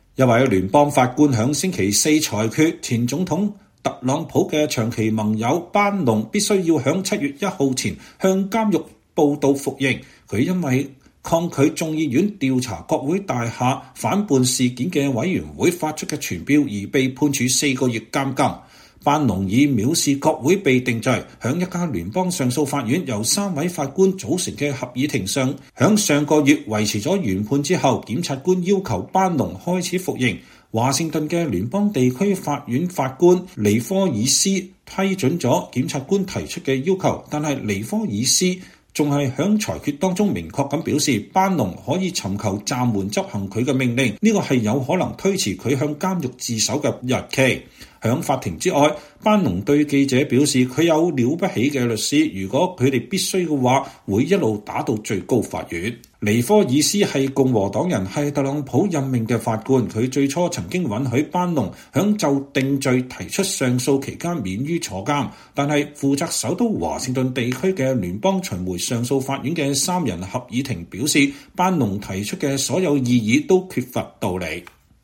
前總統特朗普的長期盟友班農在律師的伴隨下在離開華盛頓的聯邦法庭時對媒體講話。